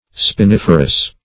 Search Result for " spiniferous" : The Collaborative International Dictionary of English v.0.48: Spiniferous \Spi*nif"er*ous\, a. [L. spinifer; spina thorn + ferre to produce.]